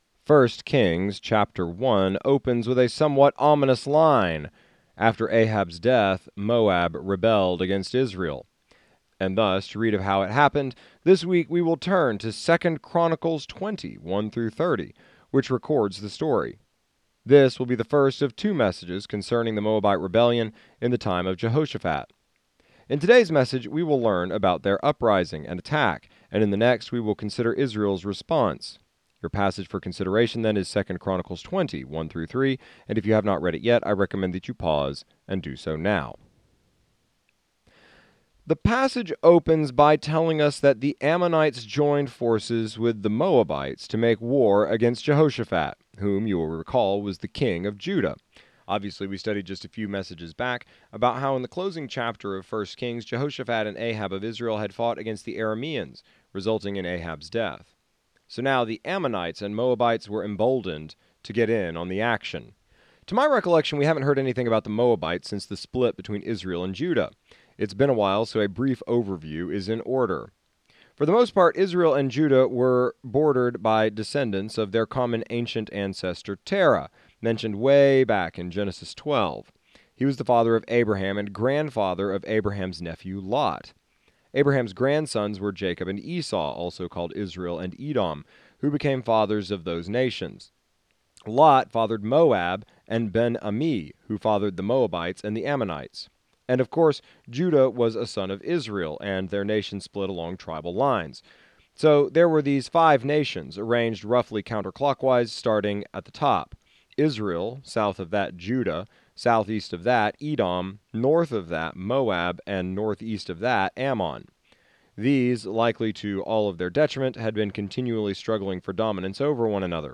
exegetical sermon series through the entire Bible